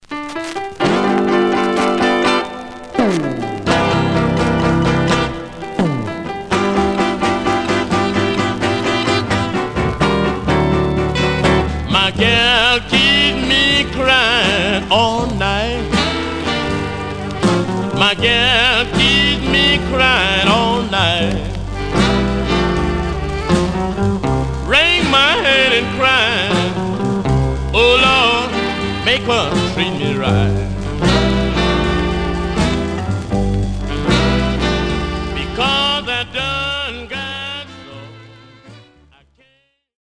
Surface noise/wear
Mono
Rythm and Blues